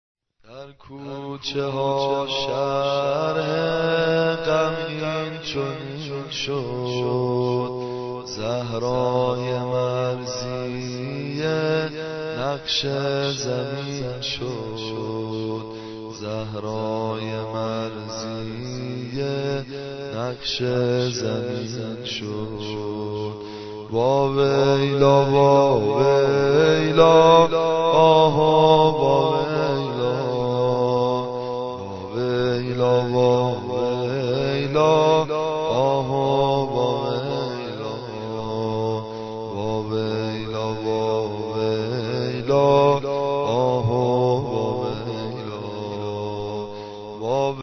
دانلود سبک